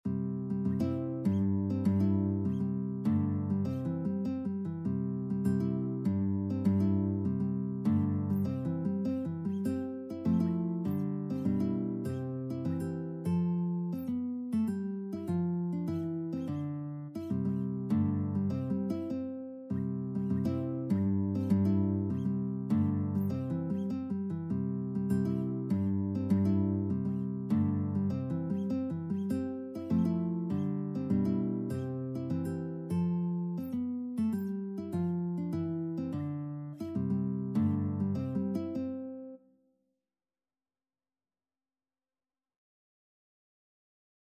Traditional Robert Burns Comin' Thru The Rye Guitar version
2/4 (View more 2/4 Music)
G3-A5
C major (Sounding Pitch) (View more C major Music for Guitar )
Easy Level: Recommended for Beginners with some playing experience
Guitar  (View more Easy Guitar Music)
Traditional (View more Traditional Guitar Music)